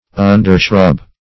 Search Result for " undershrub" : Wordnet 3.0 NOUN (1) 1. a low shrub ; The Collaborative International Dictionary of English v.0.48: Undershrub \Un"der*shrub`\, a. Partly shrublike.
undershrub.mp3